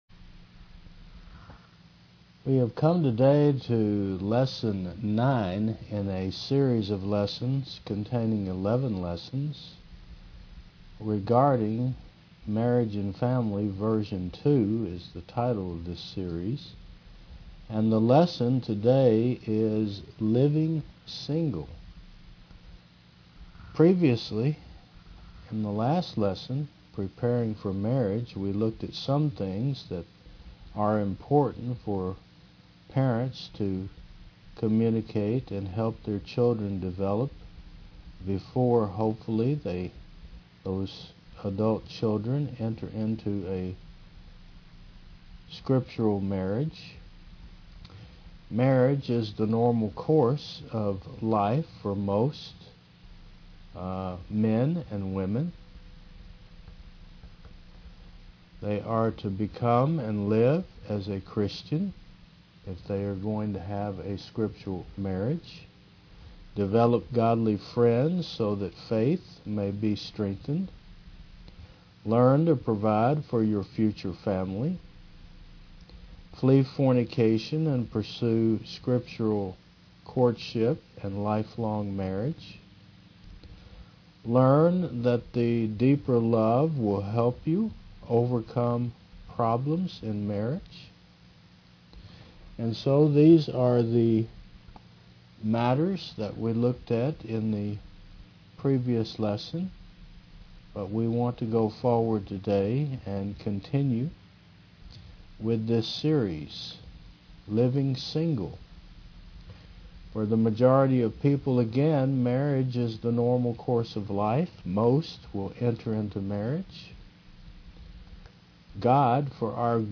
Service Type: Mon. 9 AM